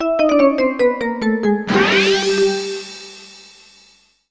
SFX无奈结束音效下载
SFX音效